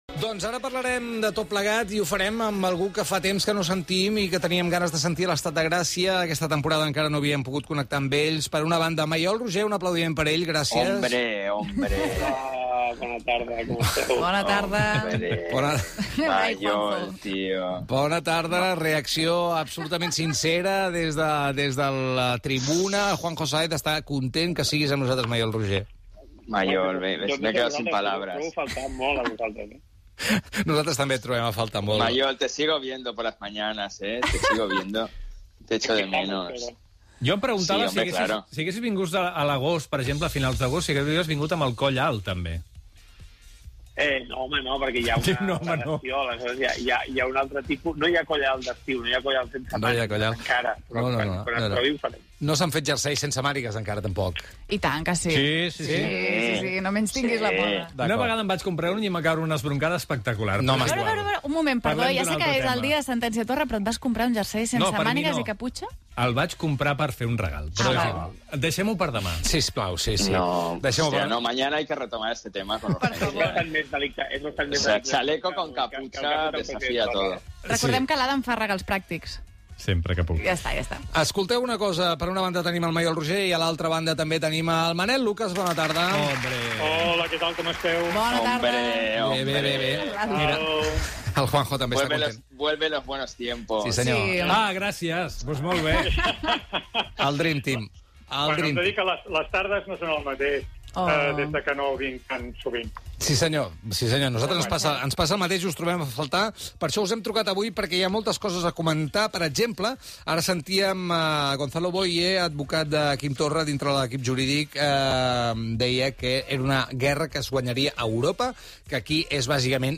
Diàleg de l'equip sobre els jerseis.
Gènere radiofònic Entreteniment